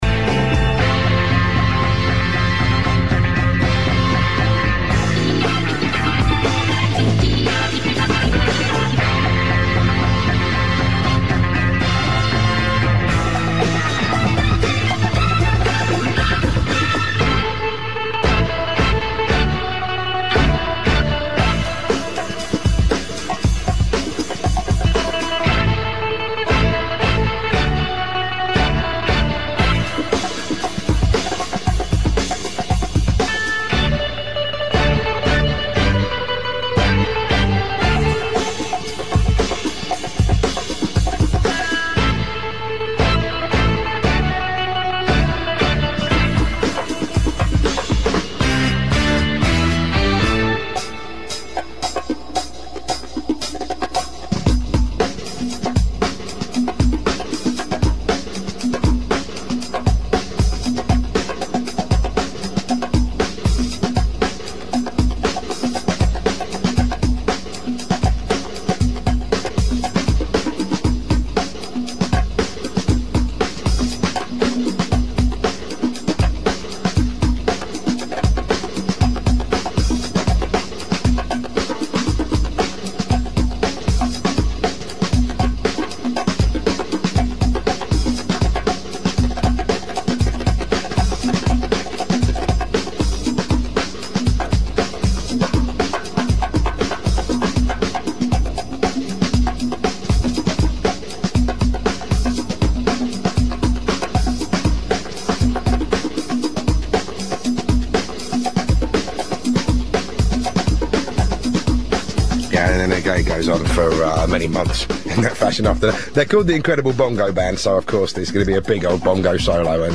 HANK MARVIN IN UN'INTERVISTA RADIOFONICA DI QUESTI GIORNI ALLA BBC RADIO 2 INGLESE